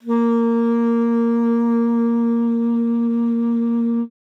42e-sax04-a#3.wav